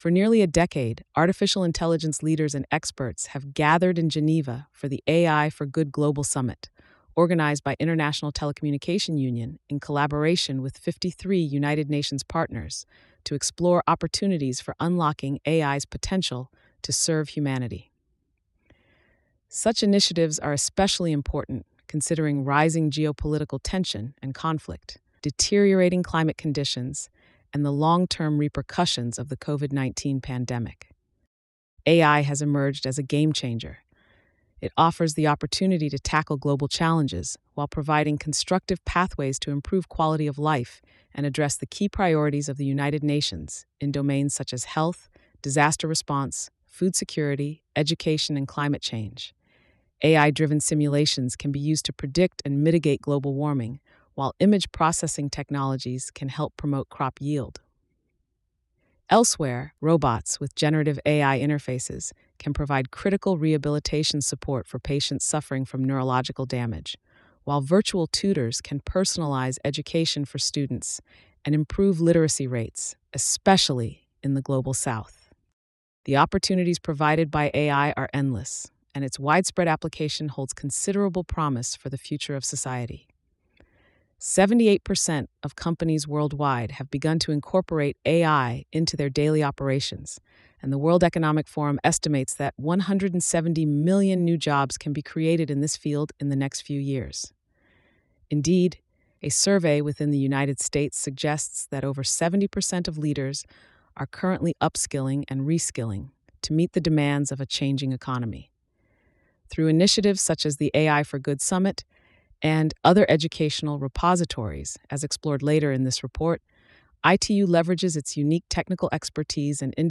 ITU text-to-speech.mp3